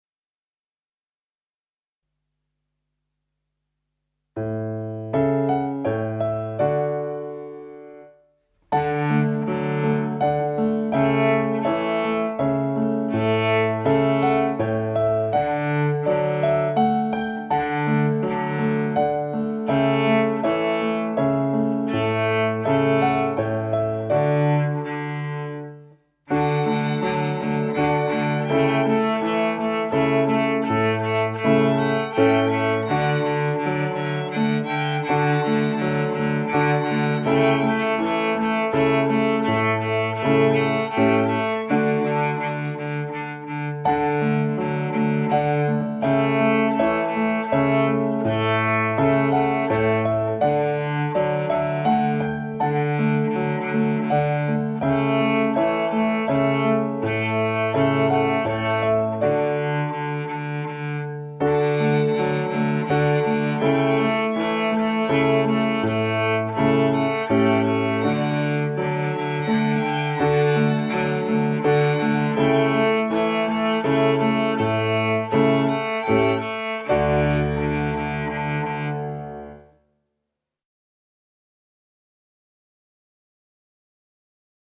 16 - STP Yr 2 Violins mozart